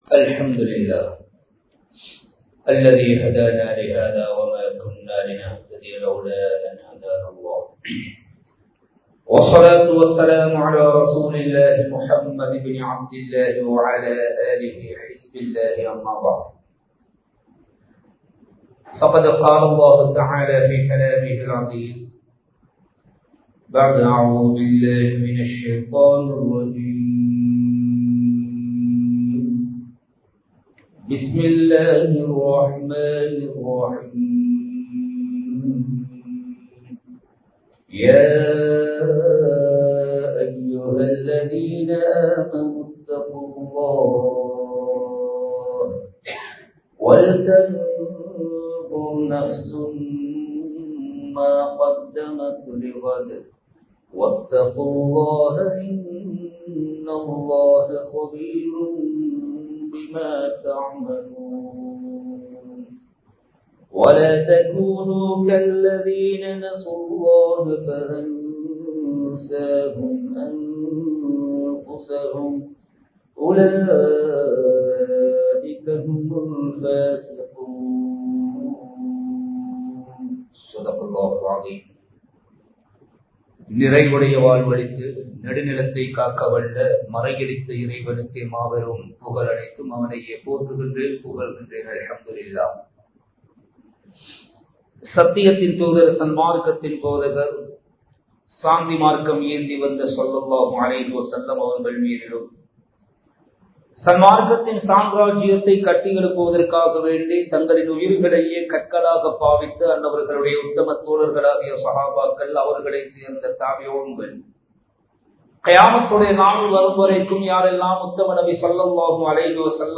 நல்லவர்களாக வாழ்வோம் | Audio Bayans | All Ceylon Muslim Youth Community | Addalaichenai
Colombo 14, Grandpass, Rahmaniya Jumua Masjidh